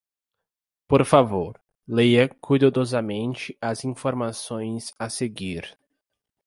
Pronounced as (IPA)
/kuj.daˌdɔ.zaˈmẽ.t͡ʃi/